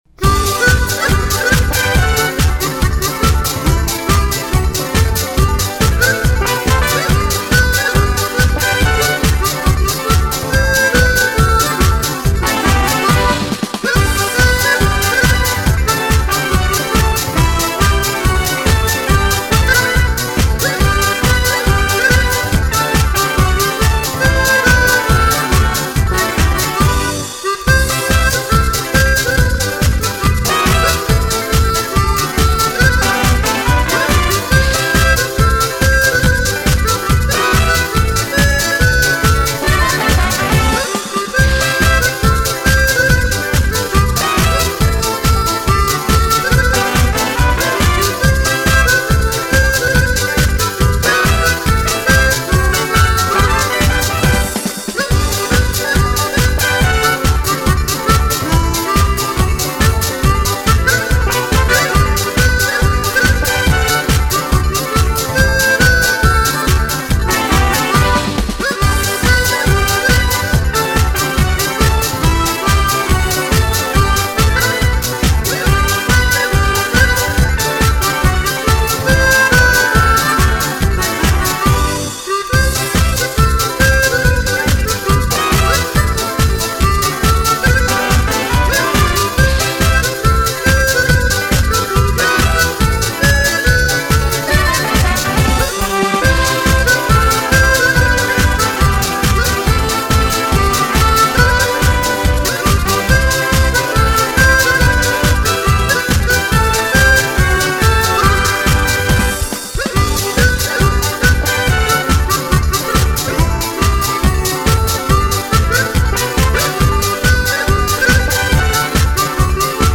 version harmonica